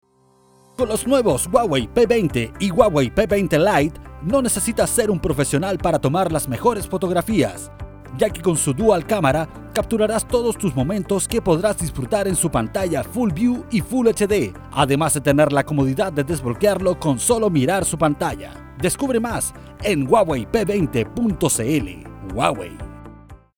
chilenisch
Sprechprobe: eLearning (Muttersprache):